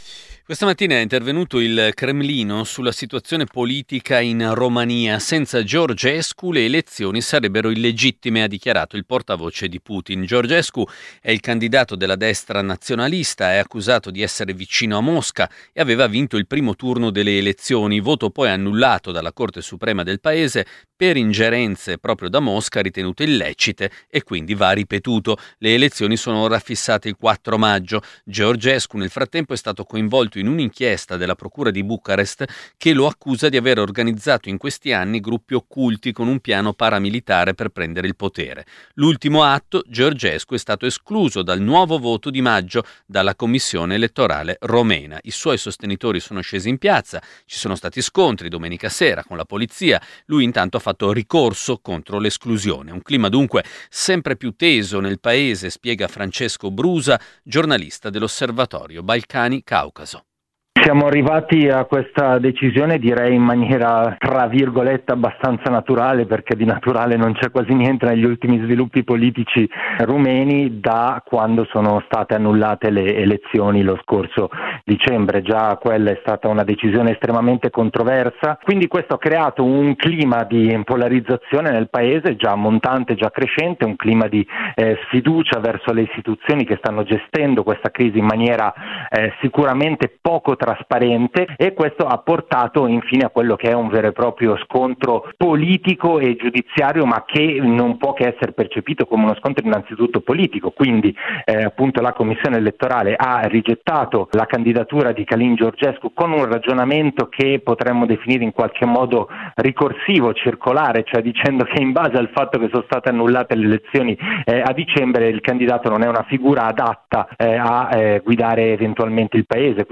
al Gr di Radio Popolare Network (11 marzo 2025)